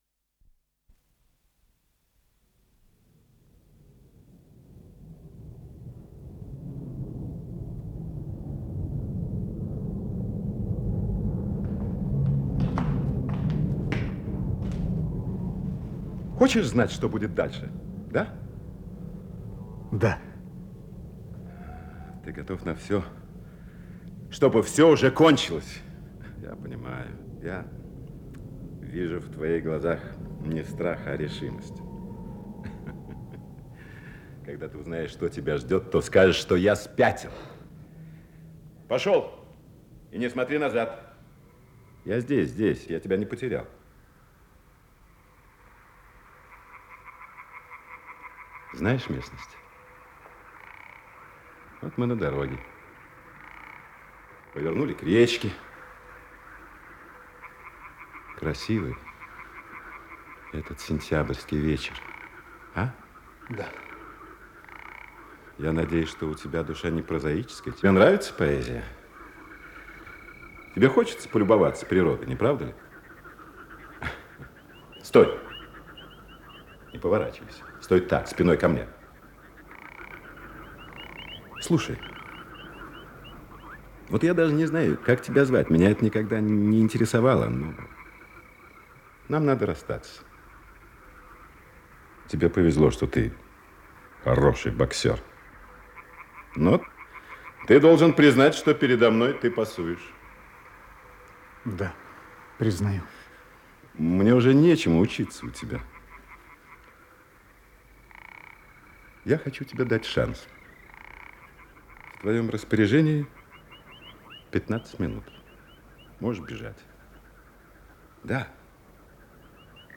Исполнитель: Артисты московских театров
Радиоспектакль